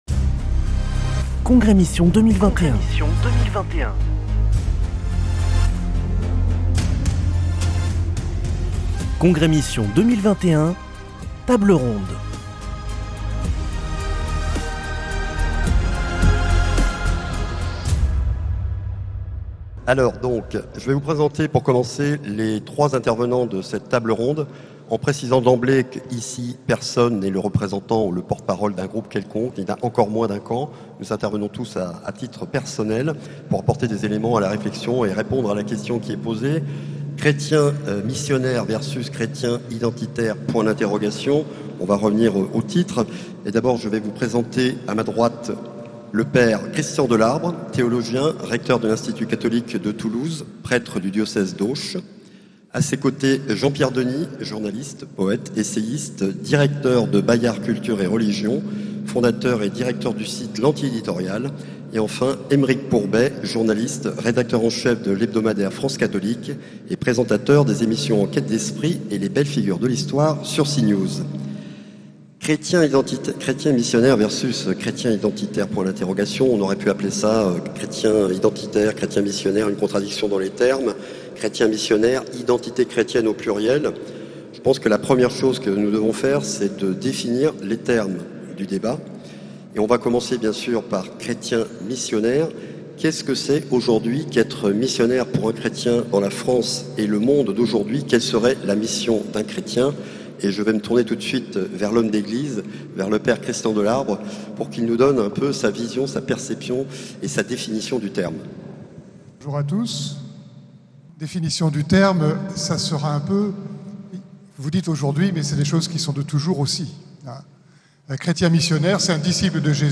Congrès Mission du 1er au 3 octobre à Toulouse - Table ronde 3